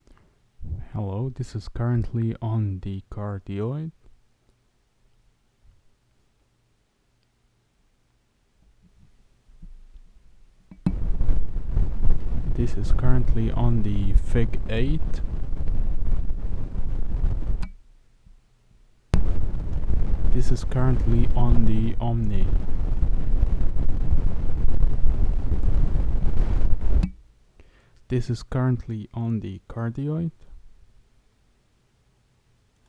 G-track pro static/interference problem
Conclusion: The static/humming happens on the FIG-8 and OMNI modes and it causes red flashes/blinks while on the CARDIOID there is noise but not as high as the FIG and OMNI
Attached the voice sample
Yes, I told them that it is noisy at some patterns and they heard the voice sample that I made and the noise was quite loud!